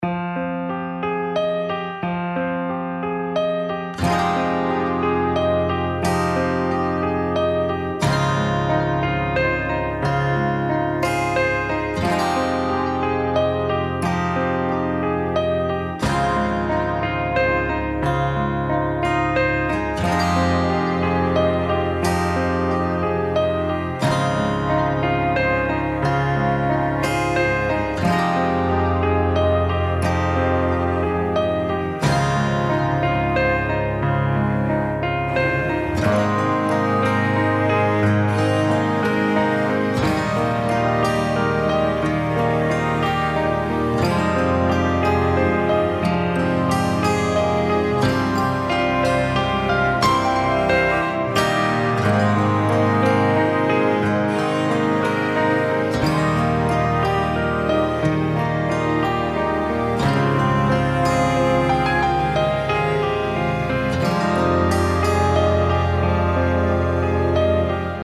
この曲をボーカルとオケを別々に書き出しました。
<オケ>
sorrowmusic_oke.mp3